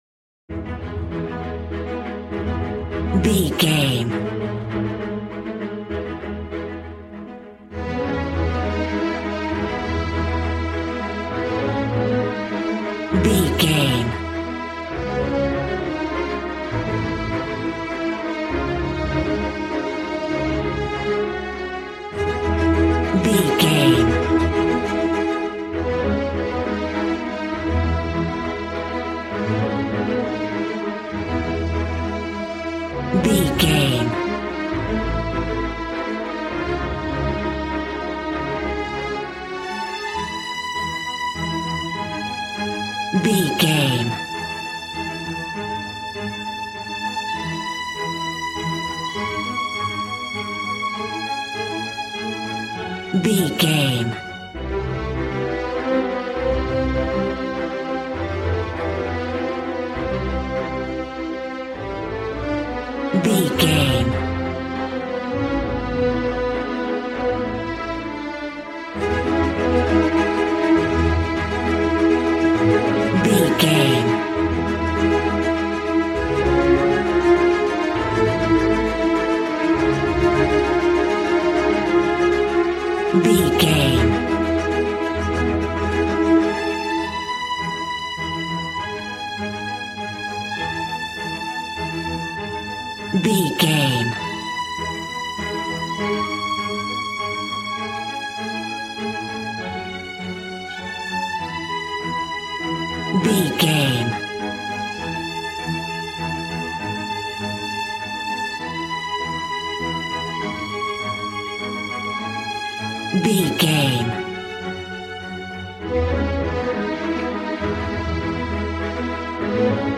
Regal and romantic, a classy piece of classical music.
Ionian/Major
E♭
Fast
regal
strings
brass